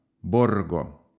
Porvoo (Finnish pronunciation: [ˈporʋoː]; Swedish: Borgå [ˈborːɡo]